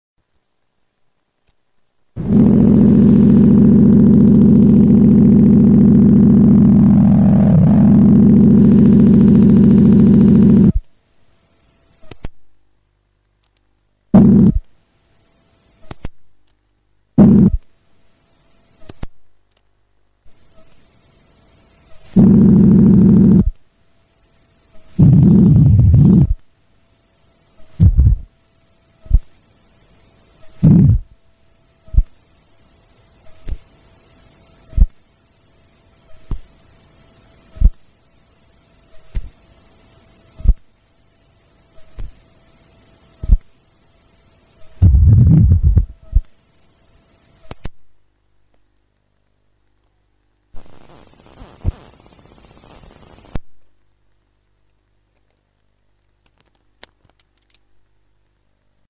noise and other weird sounds